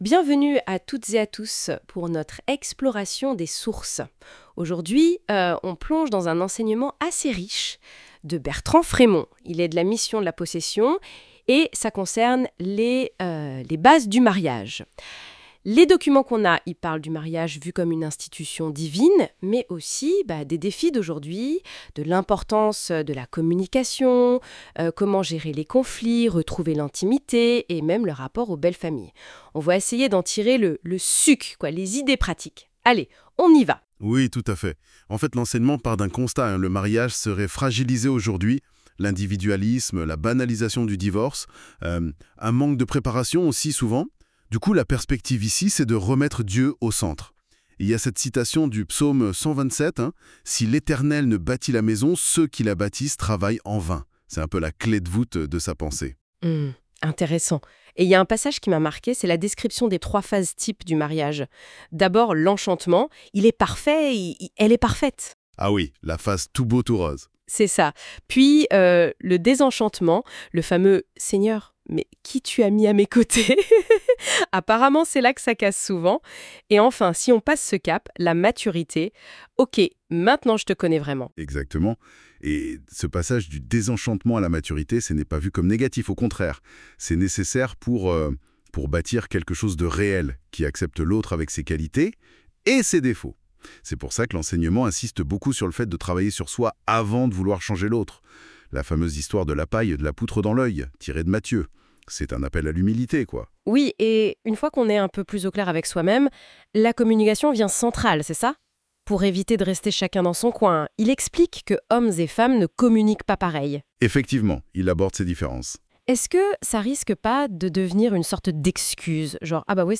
Séminaire — 2024